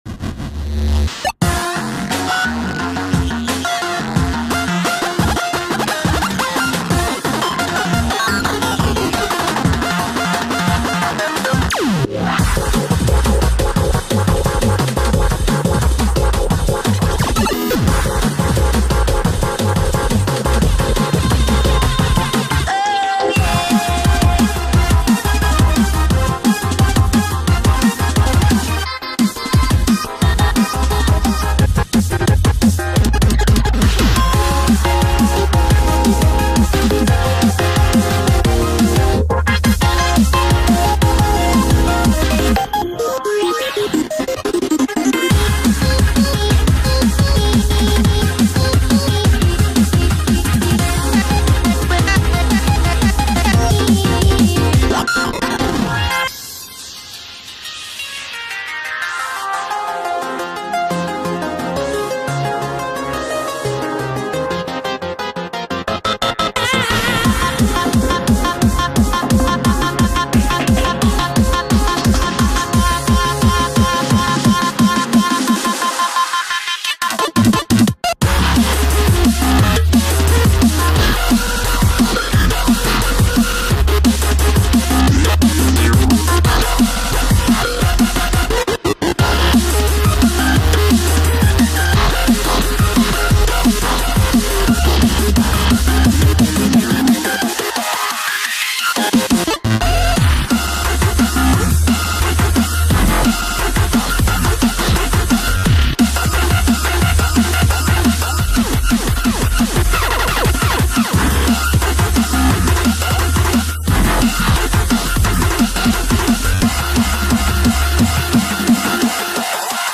BPM175
chiptune DnB